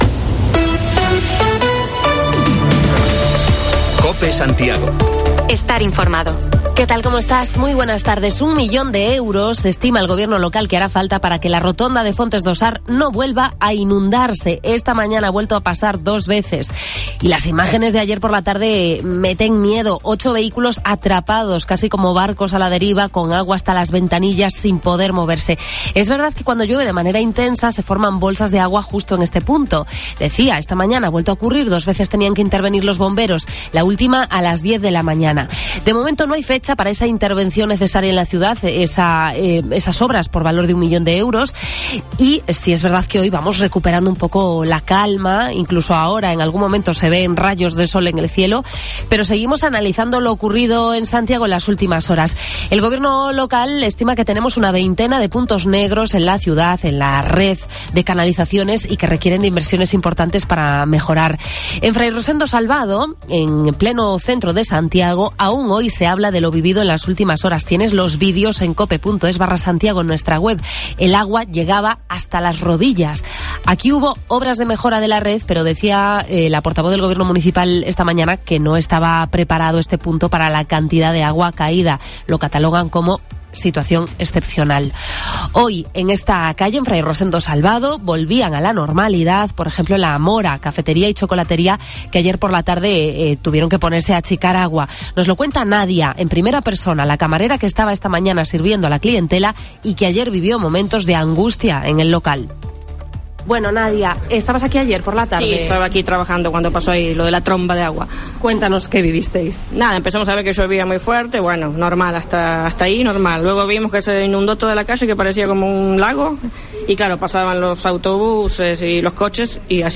AUDIO: Escuchamos testimonios sobre las inundaciones de las últimas horas en la ciudad: los afectados de Fray Rosendo Salvado hacen balance